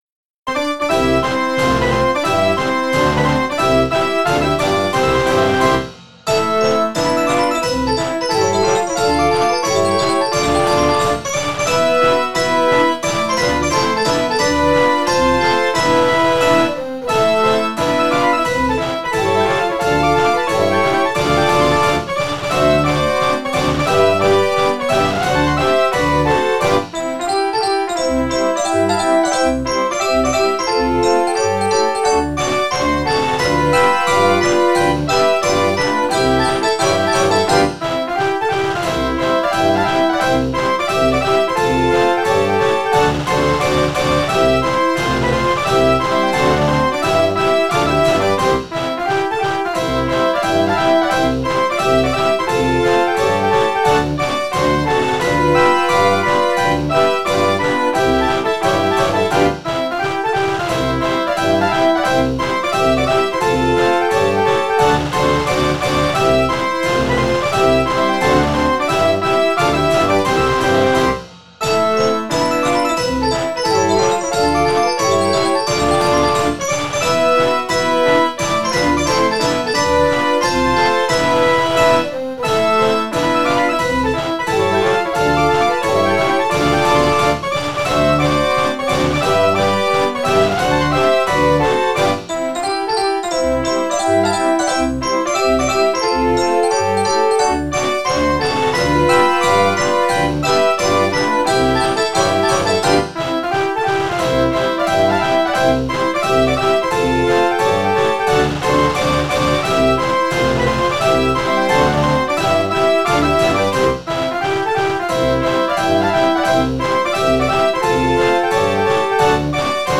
Fox Trot